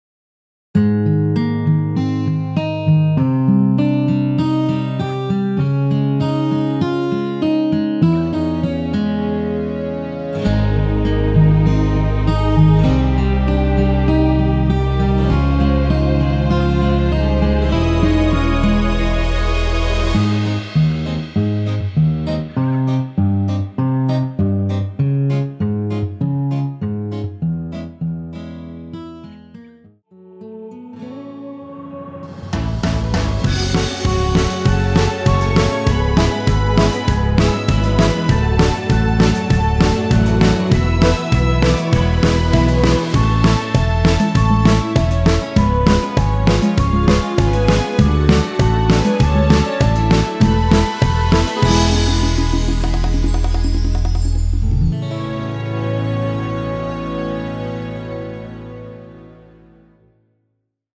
설레임과 달콤한 가사가 눈에 띄는 2013년 러브송
Ab
◈ 곡명 옆 (-1)은 반음 내림, (+1)은 반음 올림 입니다.
앞부분30초, 뒷부분30초씩 편집해서 올려 드리고 있습니다.
중간에 음이 끈어지고 다시 나오는 이유는